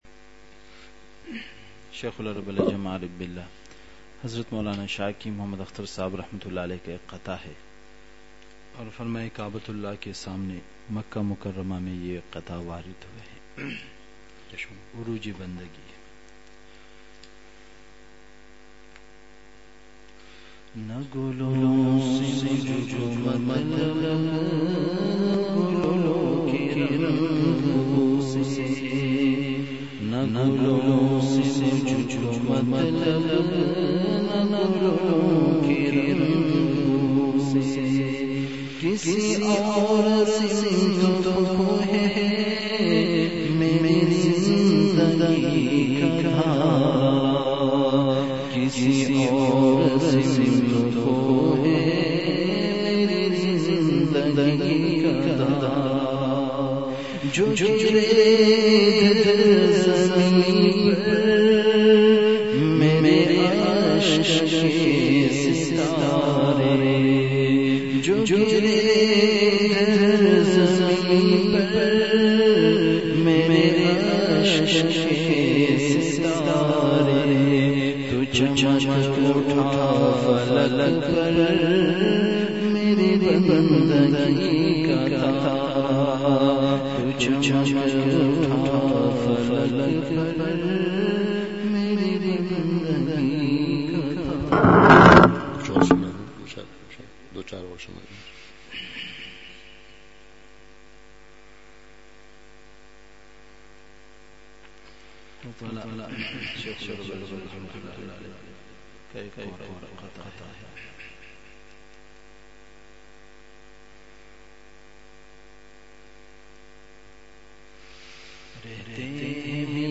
اصلاحی مجلس
مقام:مسجد اختر نزد سندھ بلوچ سوسائٹی گلستانِ جوہر کراچی
بیان کے آغاز میں اشعار پڑھے گئے۔۔